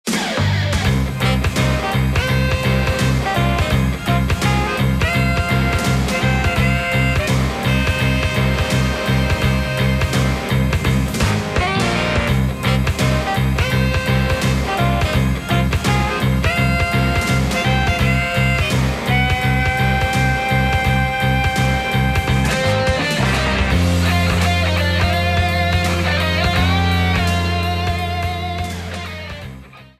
Shortened, applied fade-out, and converted to oga